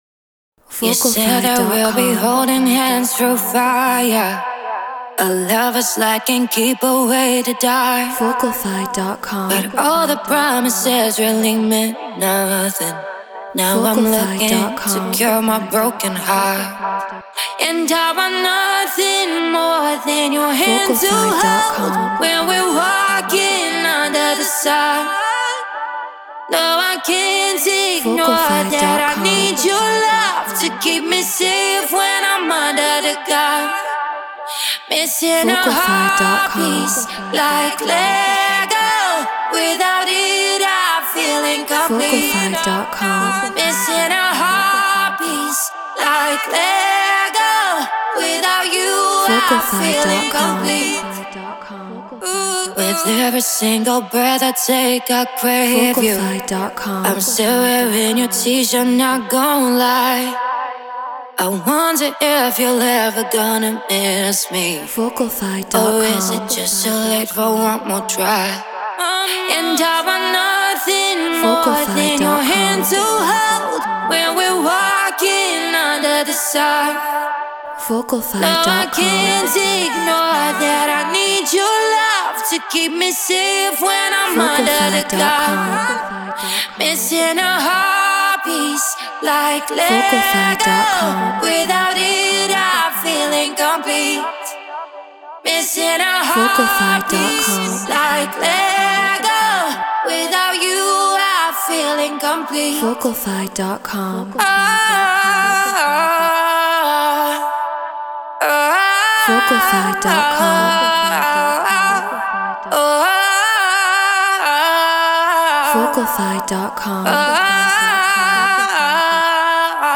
Pop 111 BPM Gmaj
Treated Room